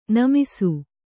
♪ namisu